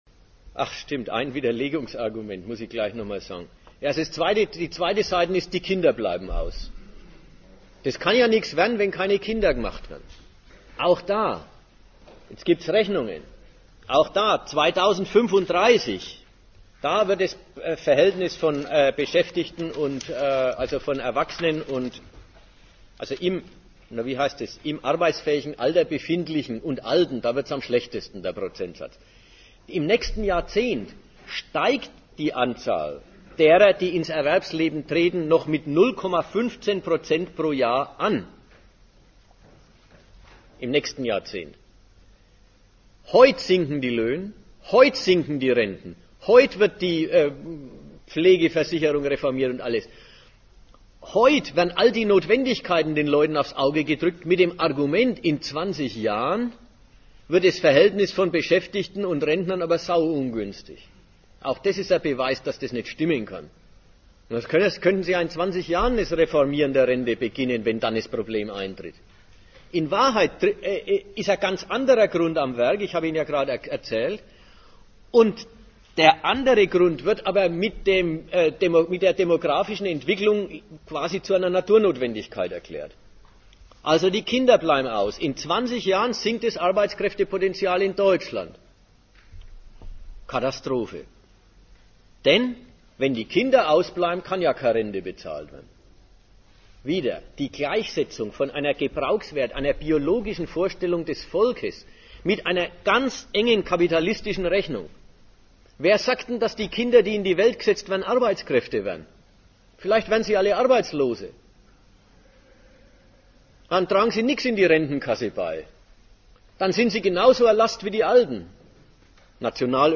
Datum 12.02.2004 Ort Nürnberg Themenbereich Volk, Nation und Moral Veranstalter Sozialistische Gruppe Dozent Gastreferenten der Zeitschrift GegenStandpunkt Die Aufnahme ist entsprechend der Gliederung gestückelt.